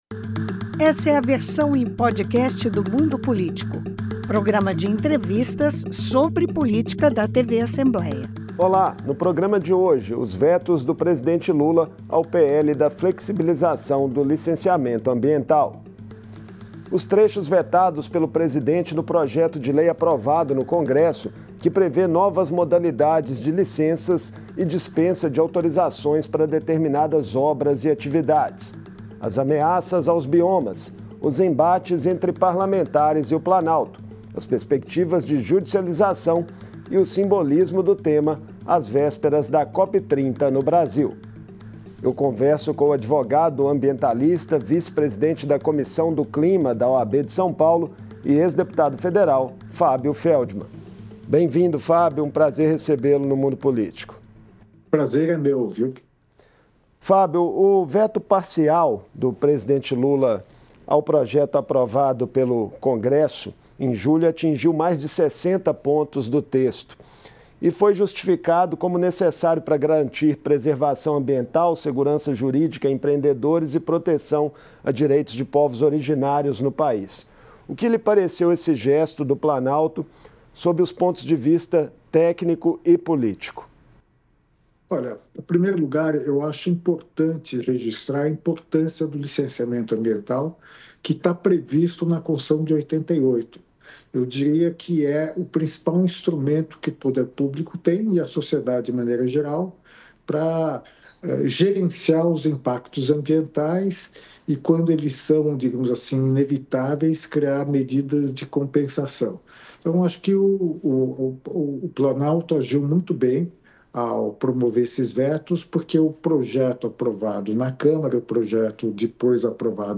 O presidente Lula vetou mais de 60 pontos do projeto de lei aprovado pelo Congresso que flexibiliza a legislação ambiental do país. Em entrevista